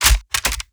Rocket Launcher
GUNMech_Rocket Launcher Reload_10_SFRMS_SCIWPNS.wav